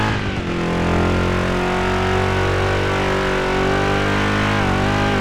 Index of /server/sound/vehicles/sgmcars/buggy
fourth_cruise.wav